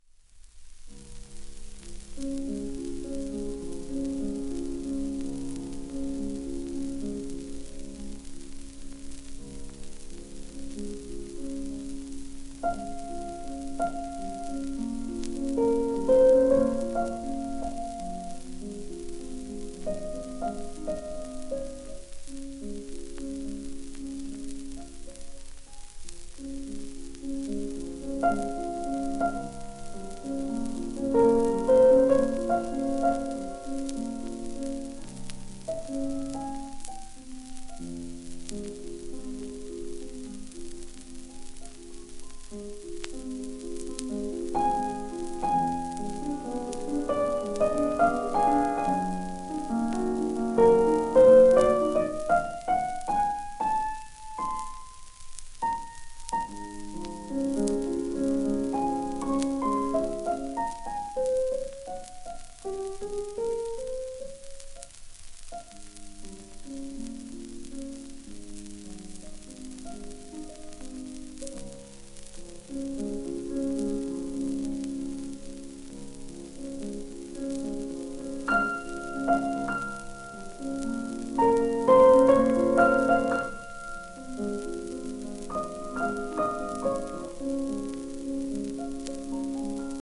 1951年録音
ウクライナ系アメリカ人ピアニスト。